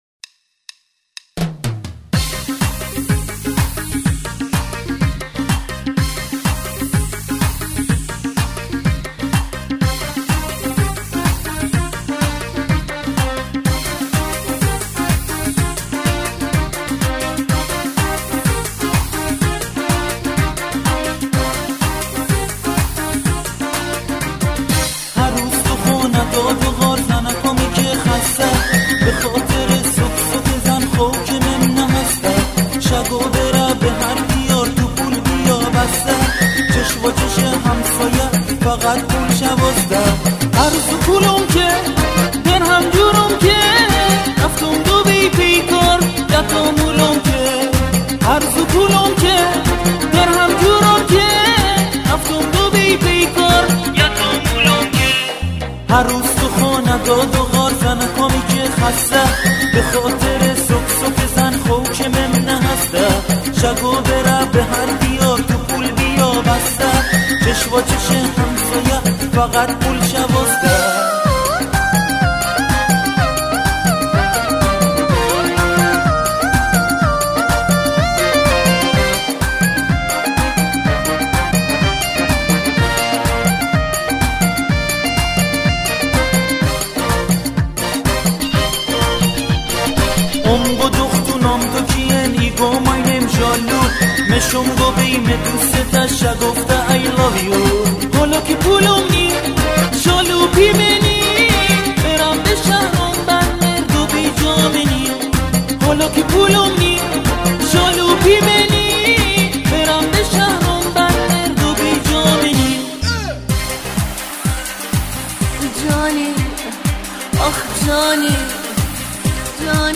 تمام تراک‌های این آلبوم به سبک و گویش بندری است.